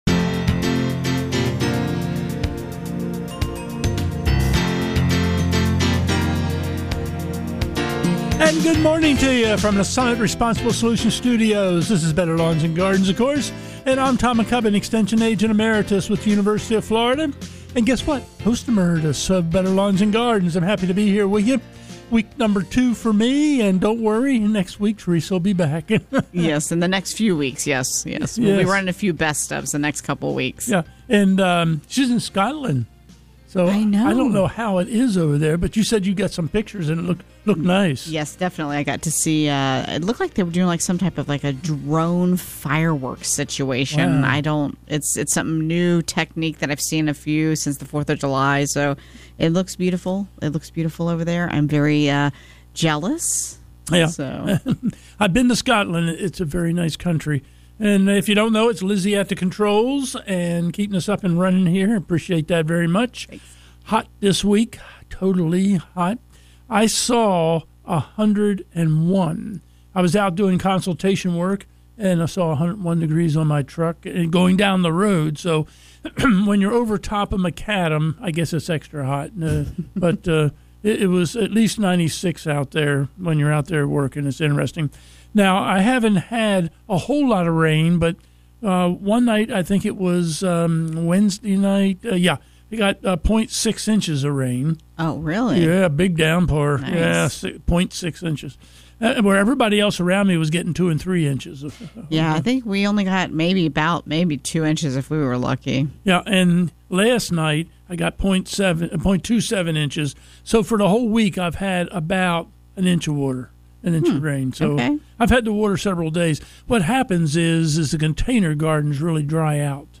Better Lawns and Gardens Hour 1 – Coming to you from the Summit Responsible Solutions Studios.